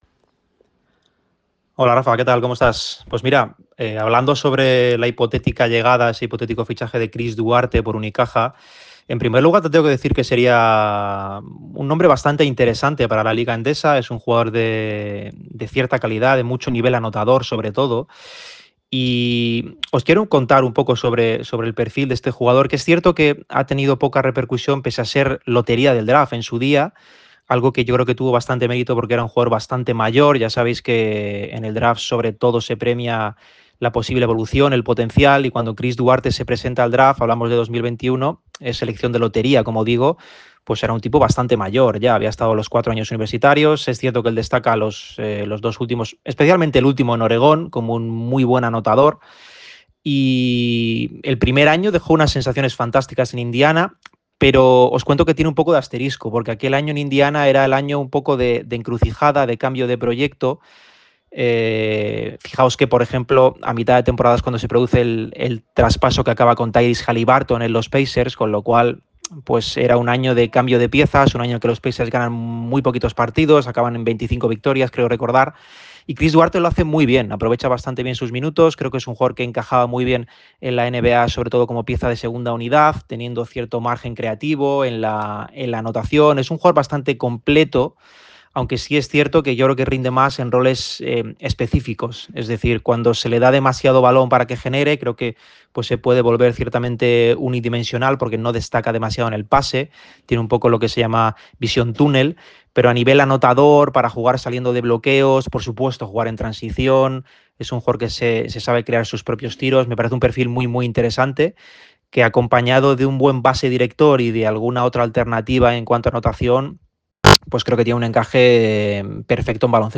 En el programa de este miércoles, se ha pasado por Radio MARCA Málaga una reconocida voz.